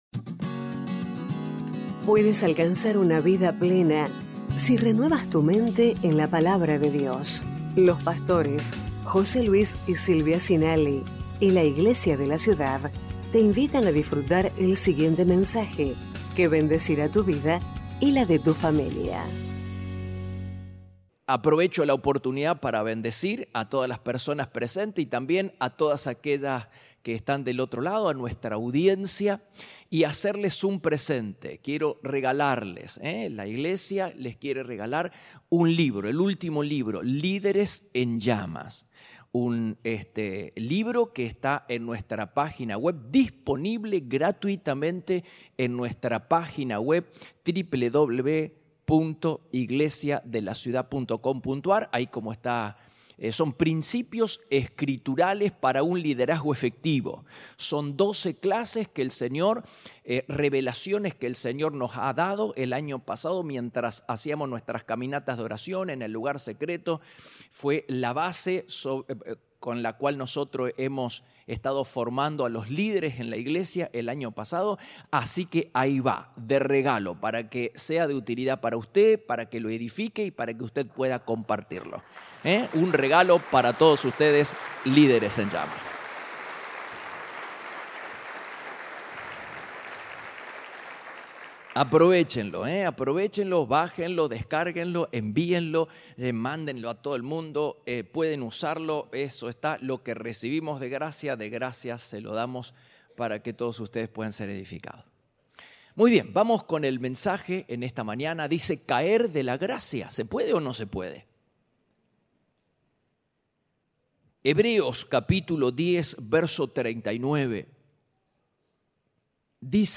Iglesia de la Ciudad - Mensajes / Caer de la gracia 8/10/2023 #1268